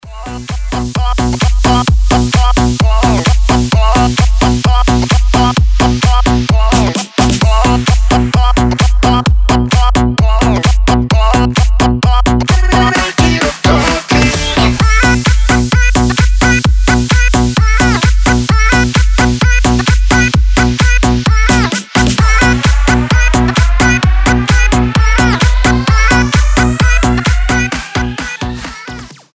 Хип-хоп
Рэп